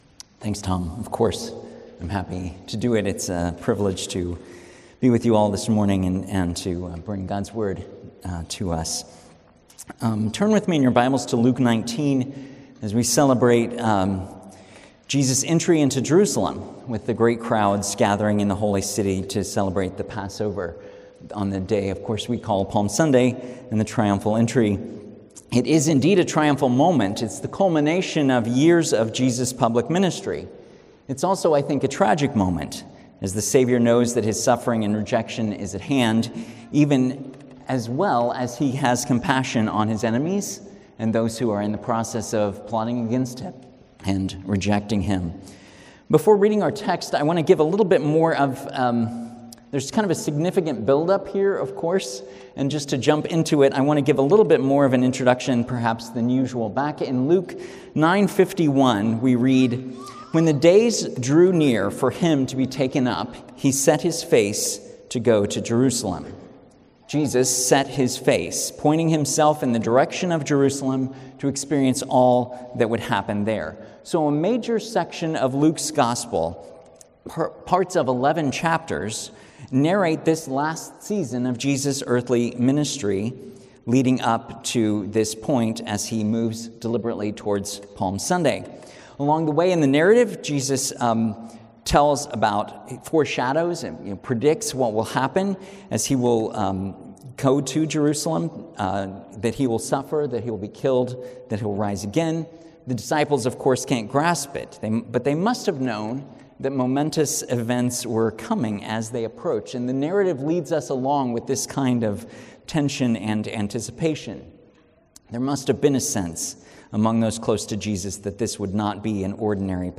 From Series: "Standalone Sermons"